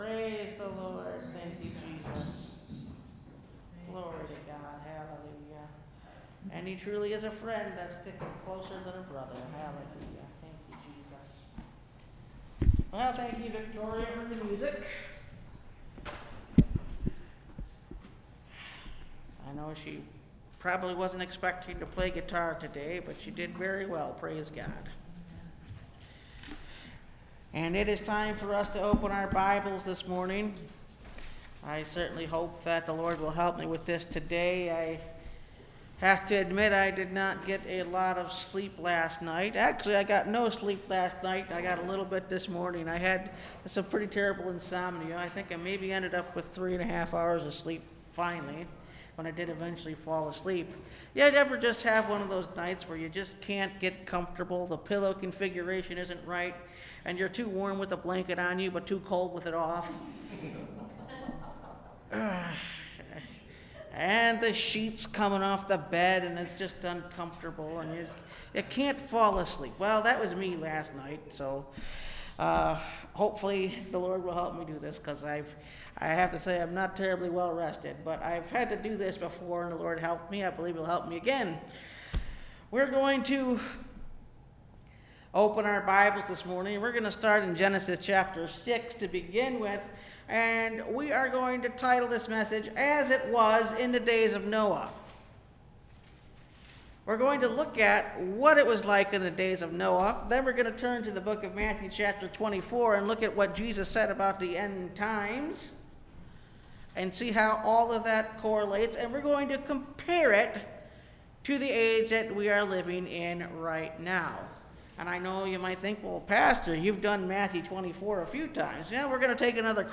As It Was In The Days Of Noah (Message Audio) – Last Trumpet Ministries – Truth Tabernacle – Sermon Library